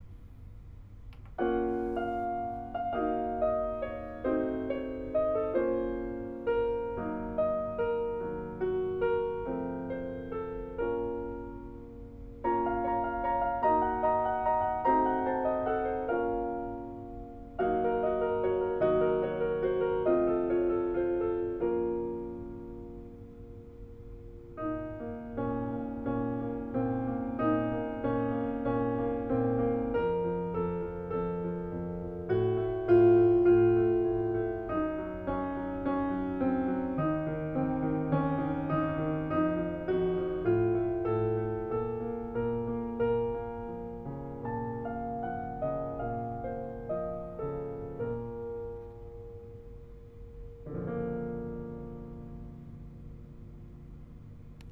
The ending is more somber than I wanted it to be.  It is slightly dissonant, discordant, and unresolved, yet it also feels like a perfect resolution!